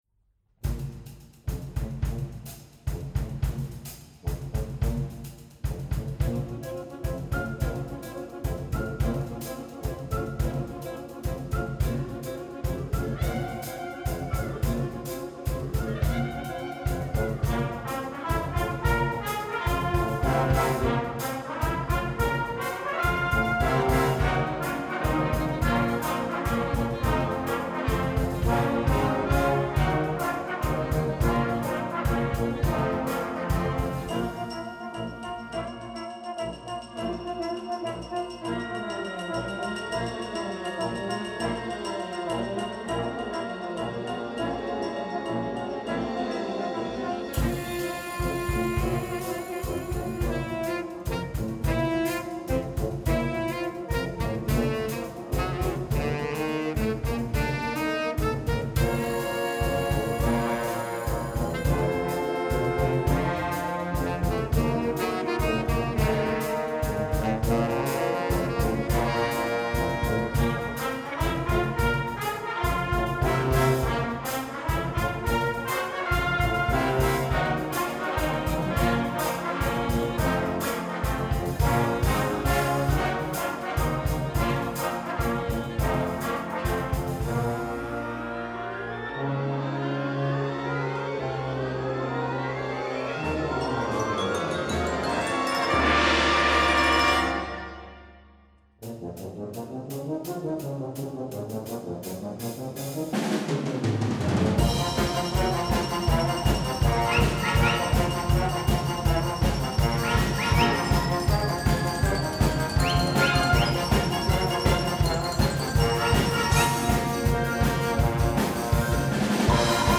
Indspillet i Tivolis Koncertsal 2017